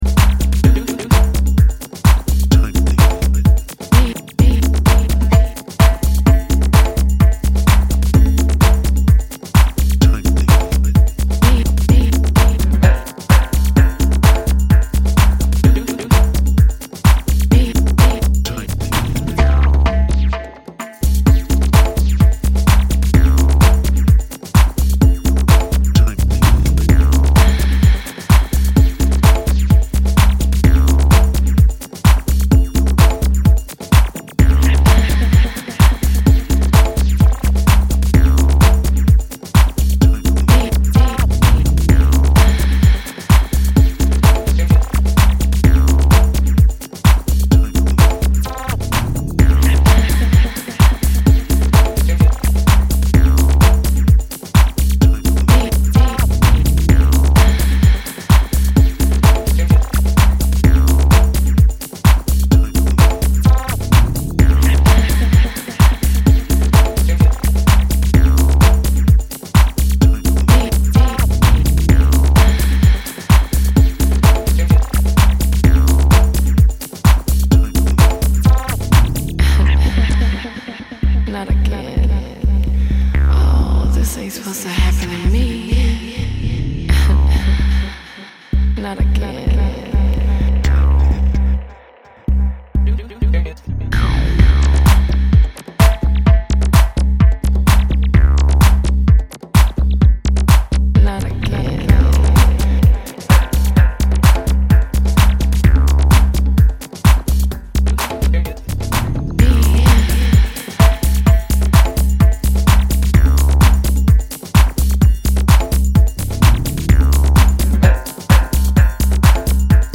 house and garage
rugged analogue grind